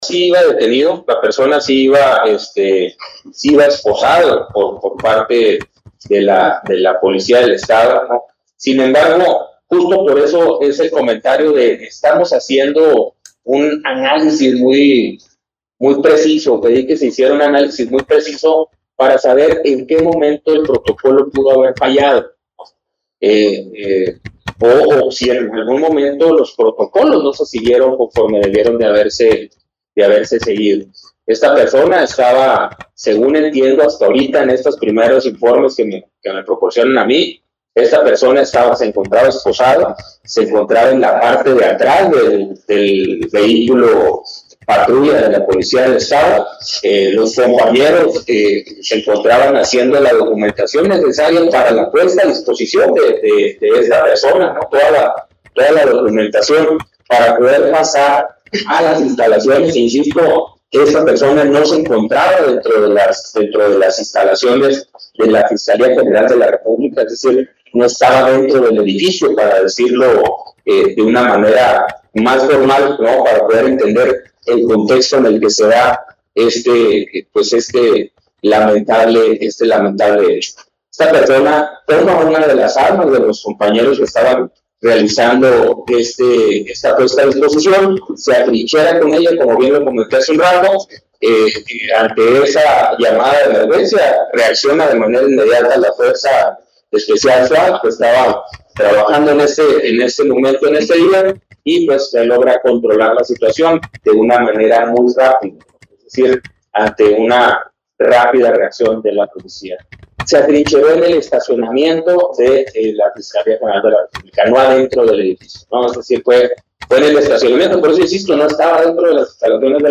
AUDIO: GILBERTO LOYA CHÁVEZ, SECRETARIO DE SEGURIDAD PÚBLICA DEL ESTADO (SSPE)